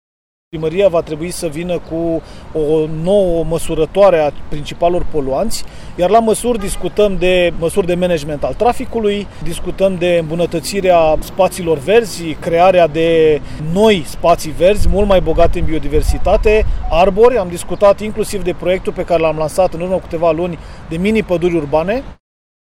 Pentru a reduce poluarea din Brașov, secretarul de stat a subliniat faptul că autoritățile locale trebuie să ia măsuri urgente: